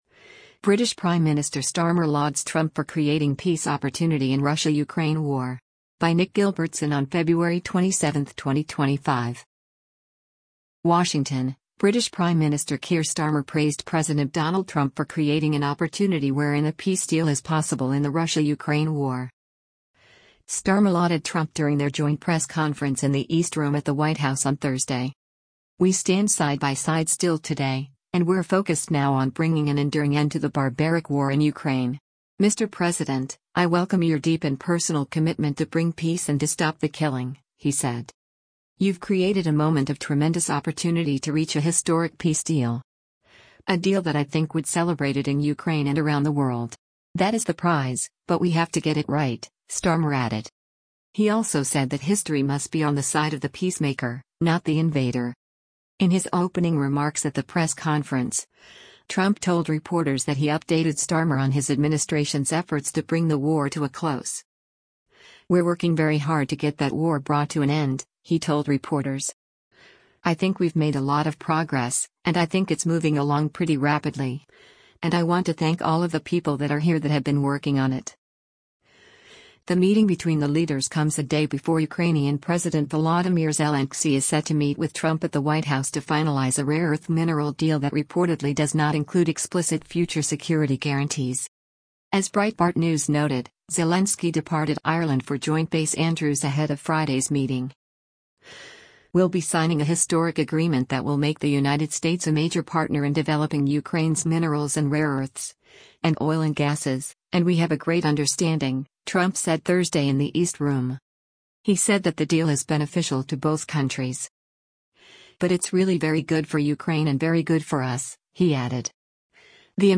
Starmer lauded Trump during their joint press conference in the East Room at the White House on Thursday.